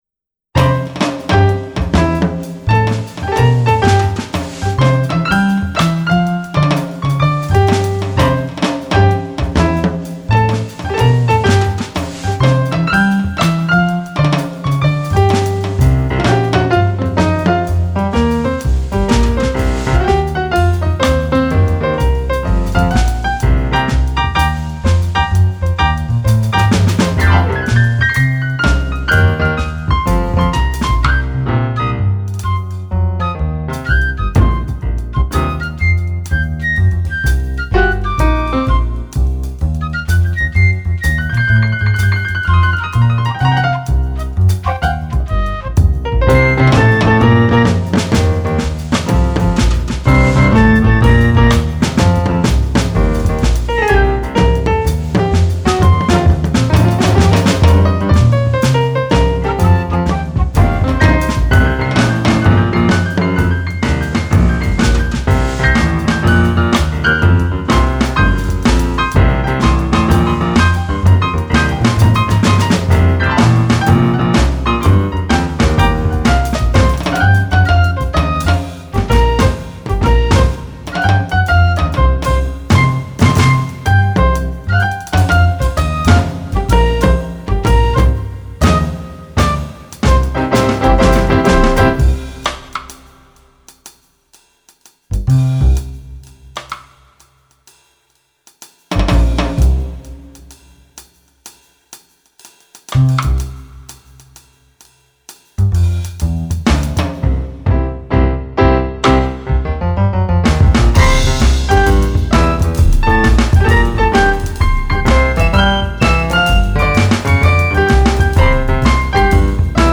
I present to you my relatively organic-sounding Mario mix:
Self-explanatory: Jazz trio plays mario music.